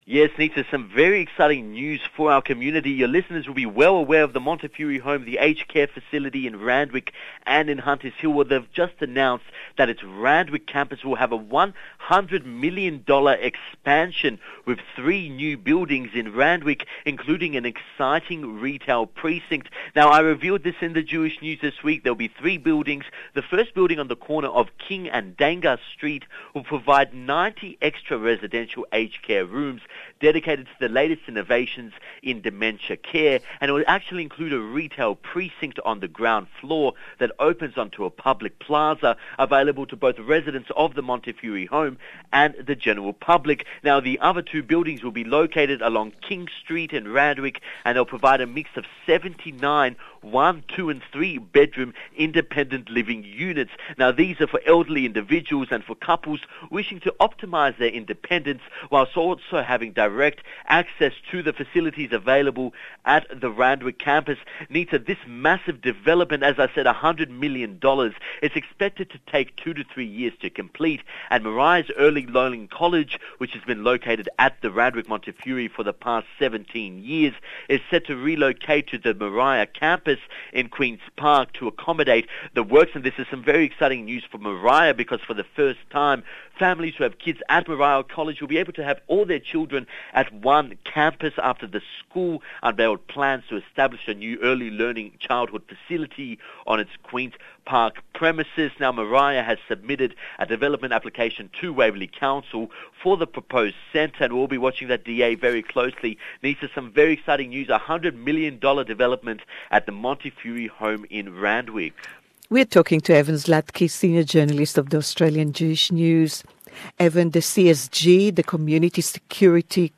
Don't miss our weekly chat